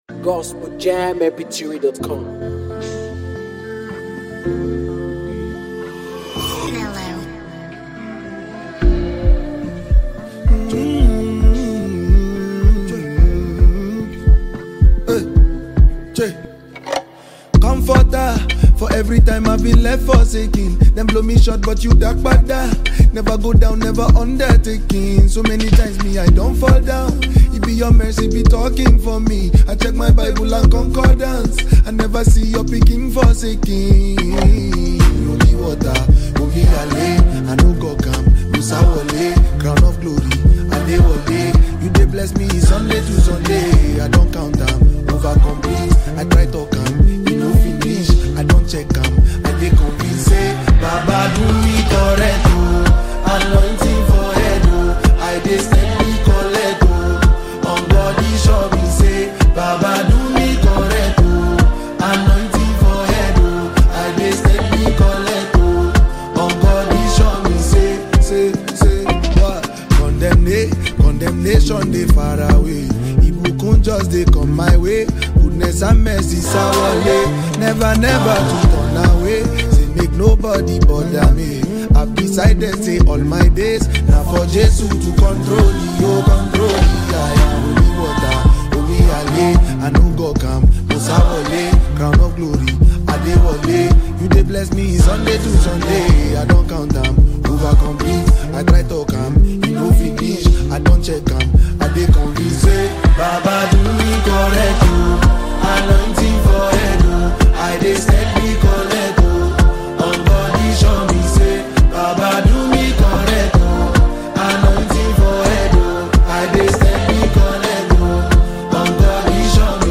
great gbedu Amapiano sound